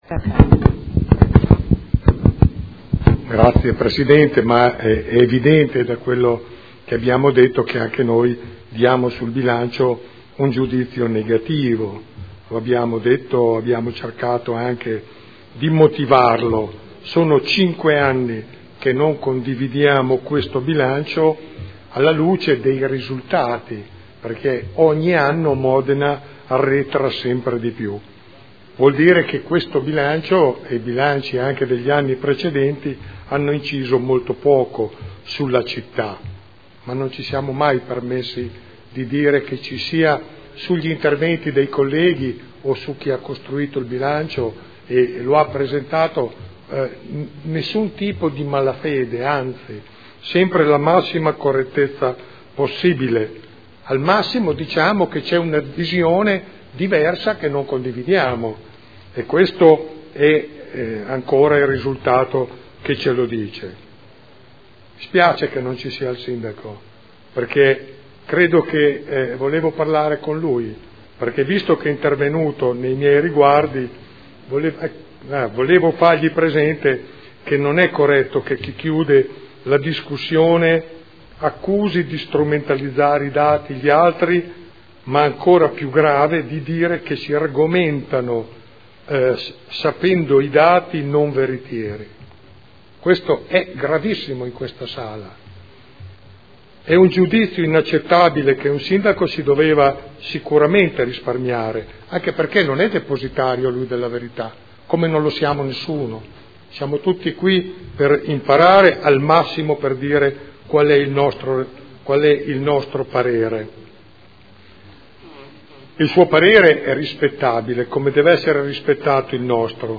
Seduta del 13 marzo. Dichiarazioni di voto sulle delibere accessorie e sul bilancio